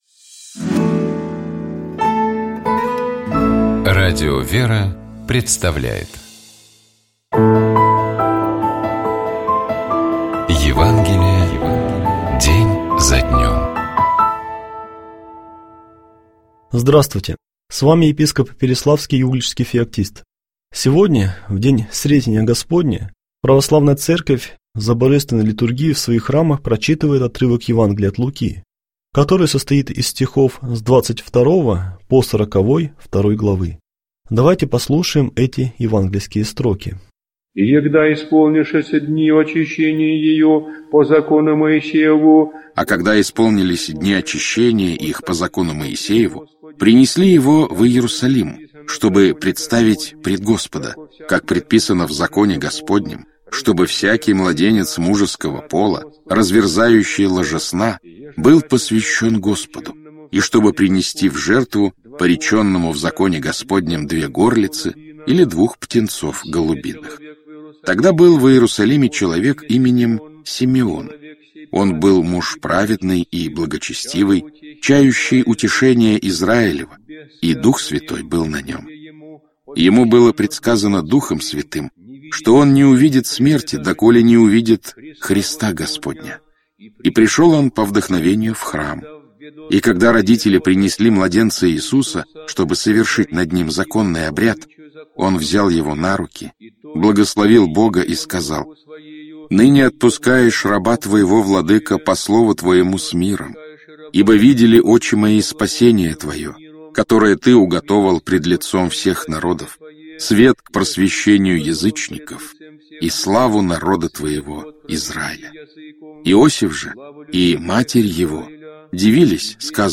епископ Феоктист Игумнов Читает и комментирует епископ Переславский и Угличский Феоктист.